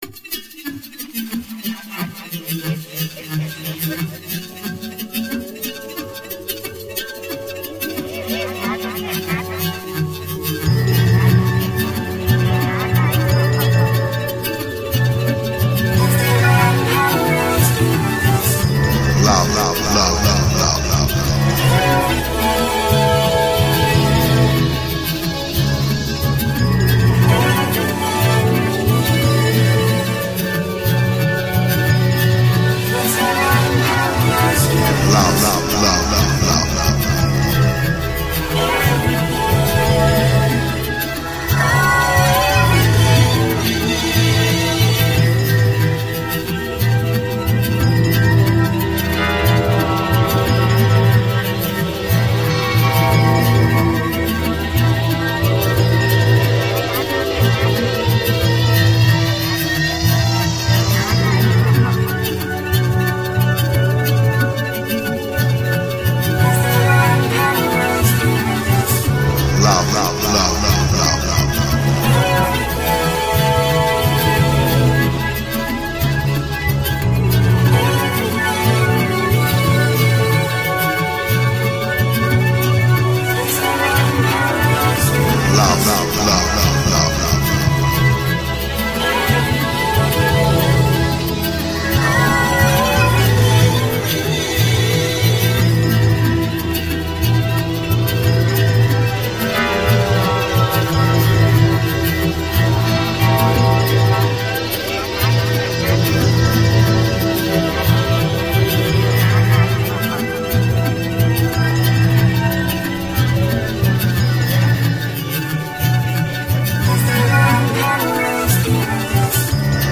remixed